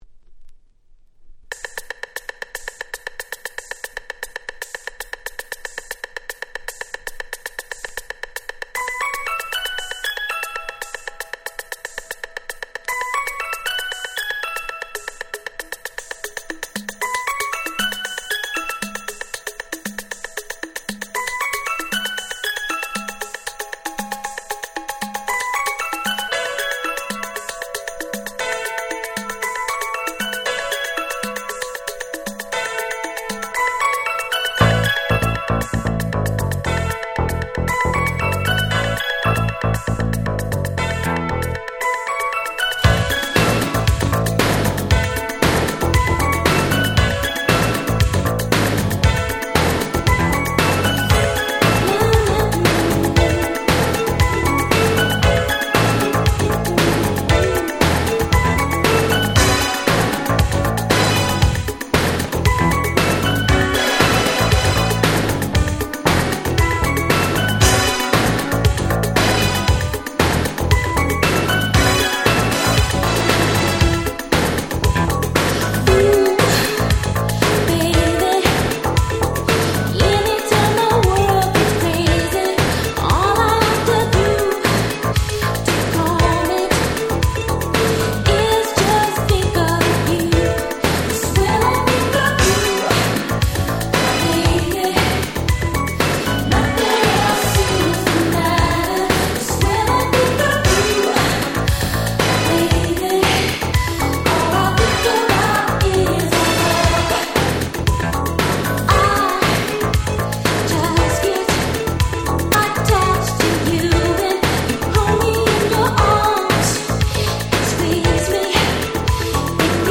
86' Super Hit R&B !!
問答無用の爽やかDanceチューン！！